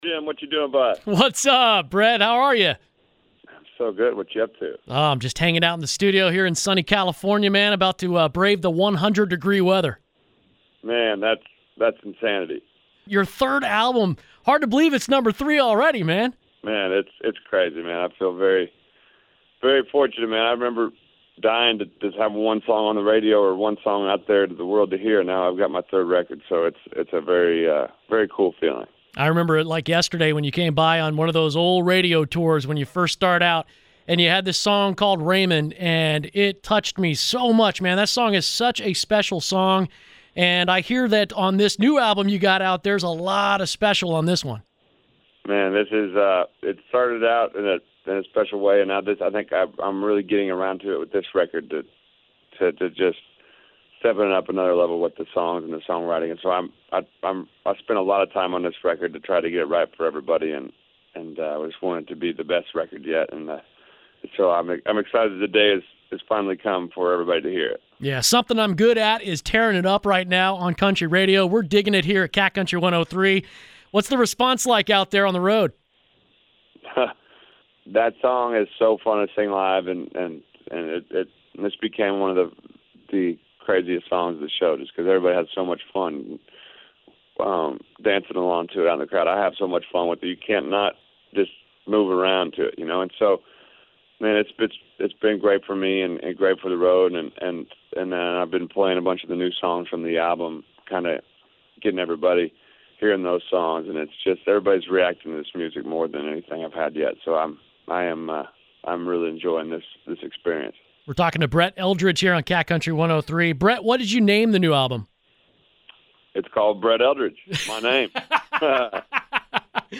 brett-eldredge-interview.mp3